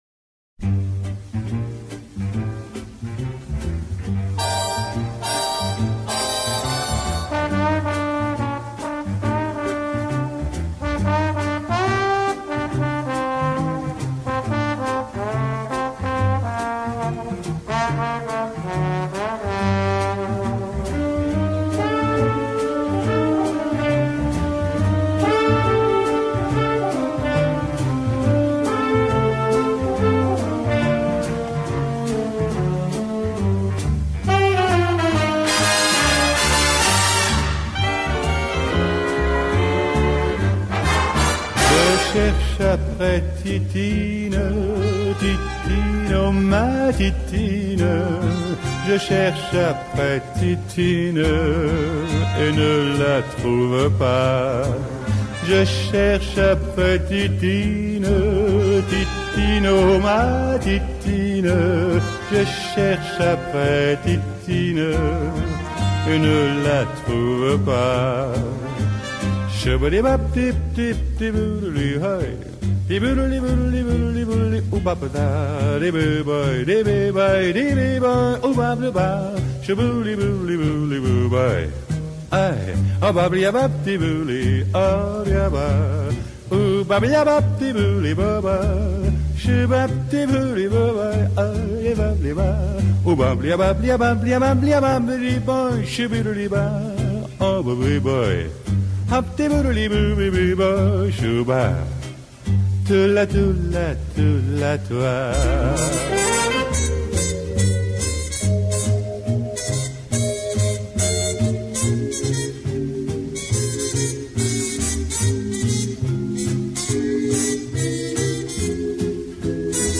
задушевные интонации